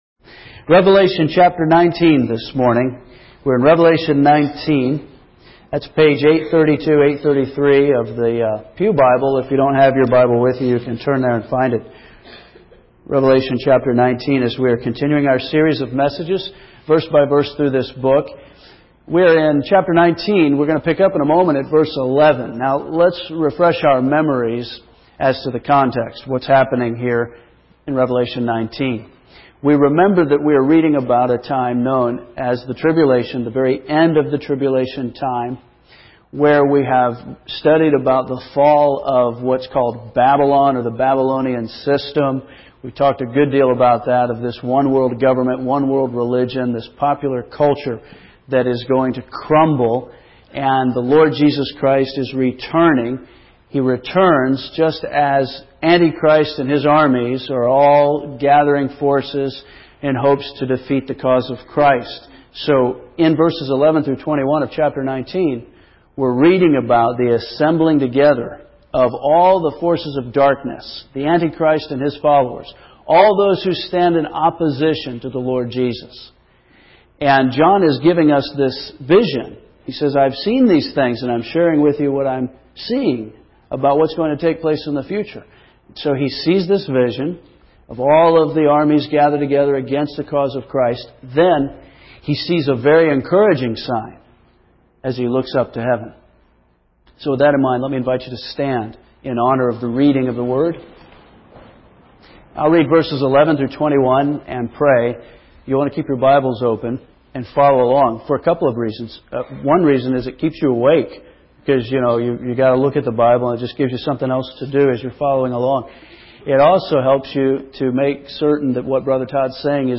First Baptist Church Henderson, KY 12-17-06 (AM)